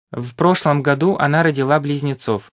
Dodatkowo program kształci rozumienie ze słuchu (10 000 nagrań rodowitych Rosjan), utrwala zasady gramatyki i ortografii, a opcja nagrywania przez mikrofon pozwala nabrać właściwego akcentu.